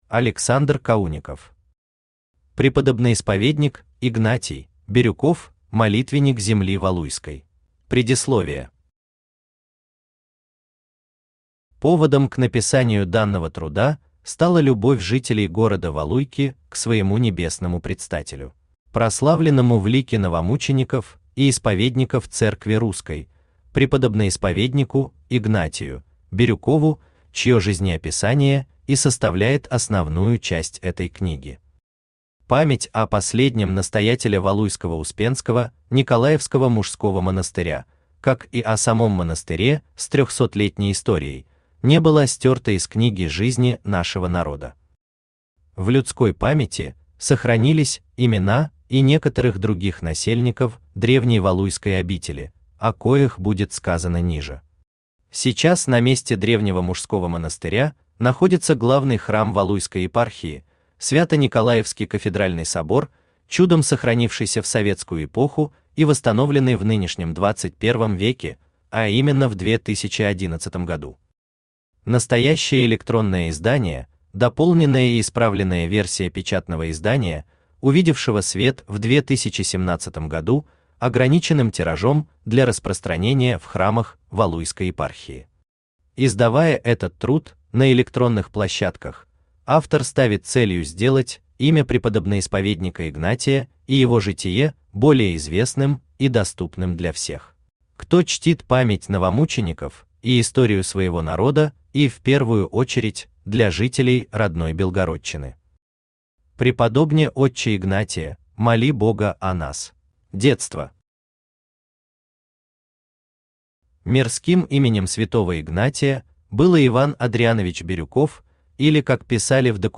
Аудиокнига Преподобноисповедник Игнатий (Бирюков): Молитвенник земли Валуйской | Библиотека аудиокниг
Aудиокнига Преподобноисповедник Игнатий (Бирюков): Молитвенник земли Валуйской Автор Александр Сергеевич Каунников Читает аудиокнигу Авточтец ЛитРес.